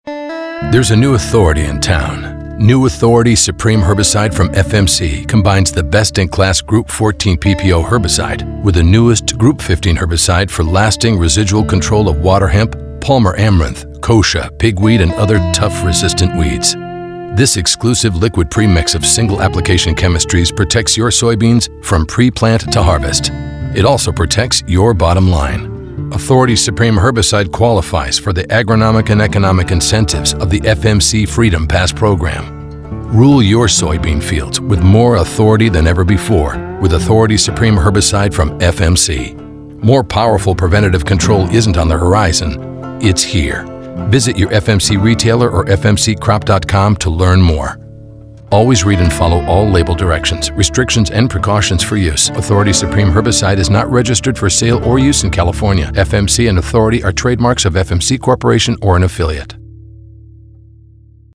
Four produced radio spots supporting print and digital campaigns.
authority-supreme-radio-60.mp3